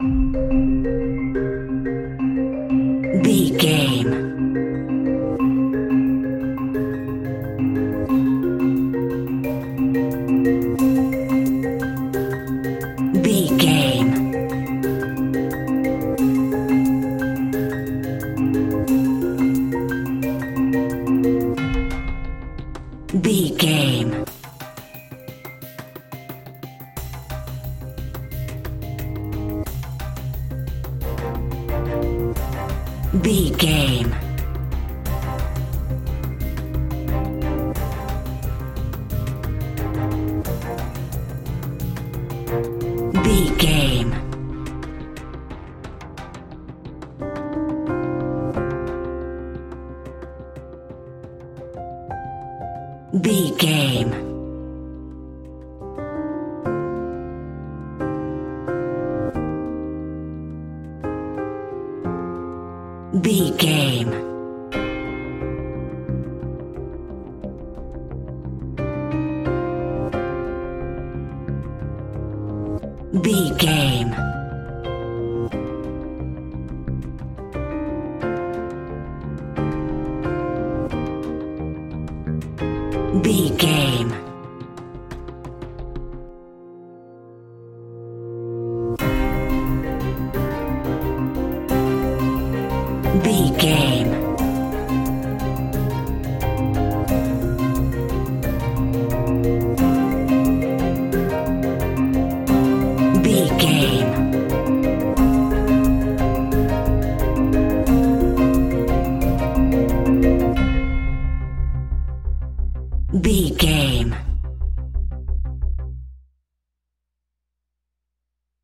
Ionian/Major
C♭
electronic
techno
trance
synths
synthwave